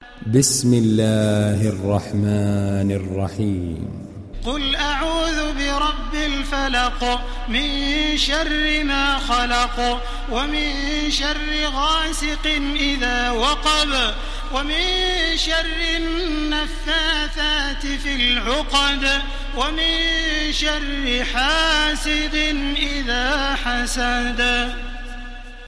Download Surah Al Falaq Taraweeh Makkah 1430